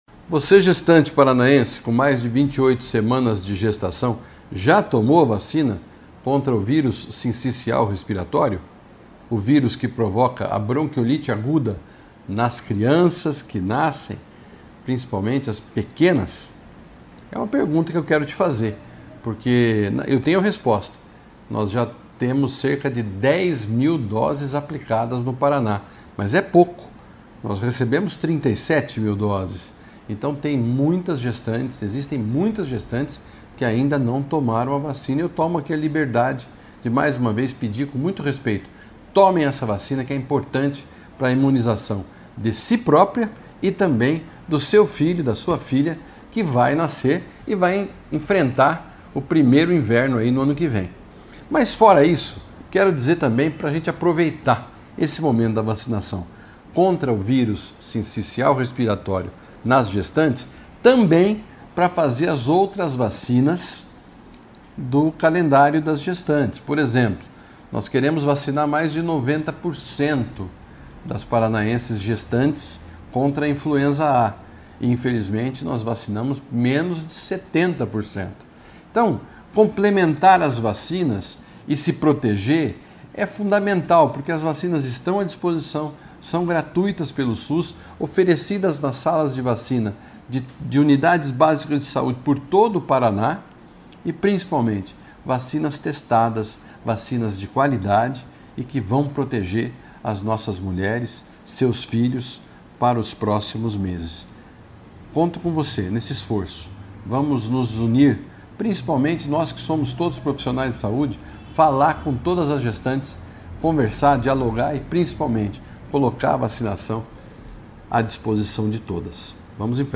Sonora do secretário da Saúde, Beto Preto, sobre o alerta para vacinas essenciais às mulheres gestantes